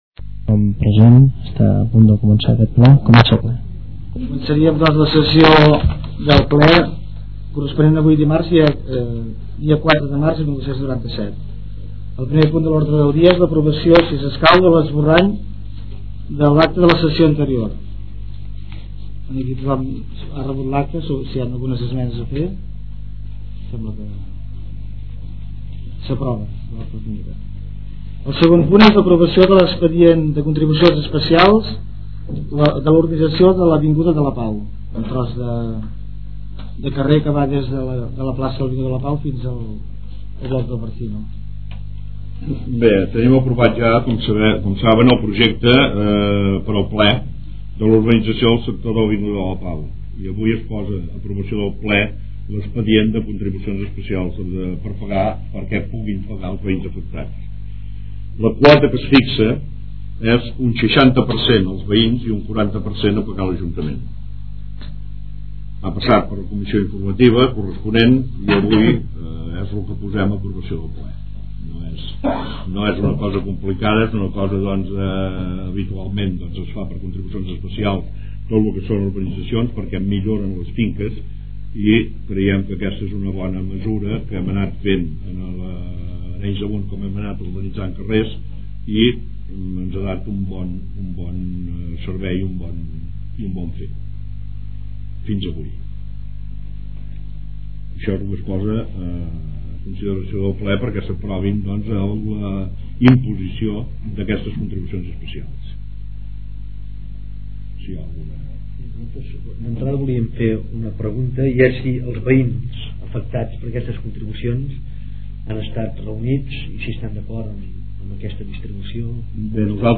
Ple d'ajuntament